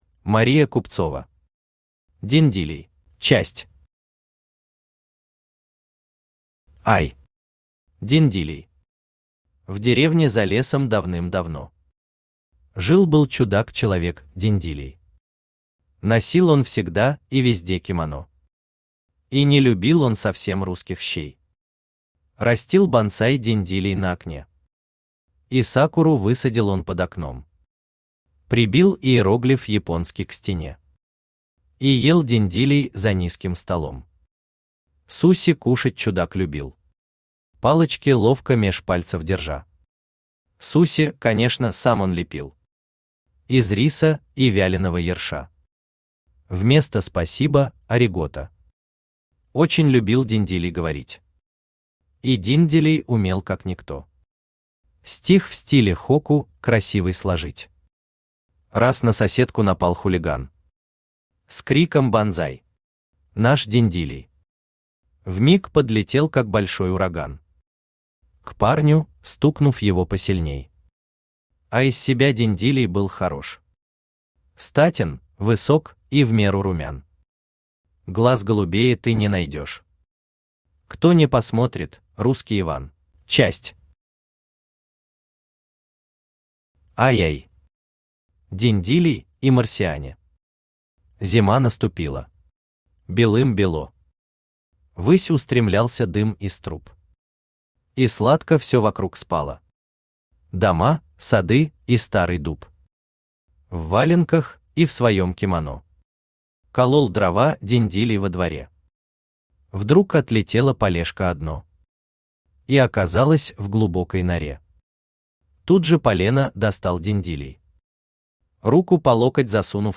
Aудиокнига Диндилей Автор Мария Купцова Читает аудиокнигу Авточтец ЛитРес.